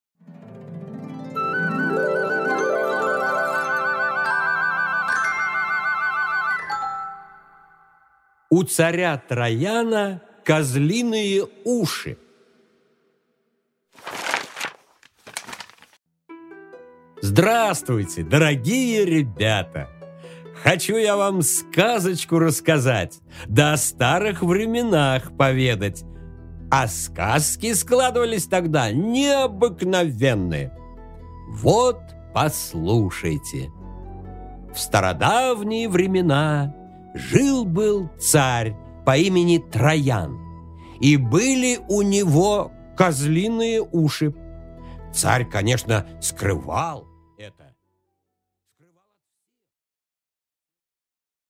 Aудиокнига У царя Трояна козлиные уши! Автор Народное творчество.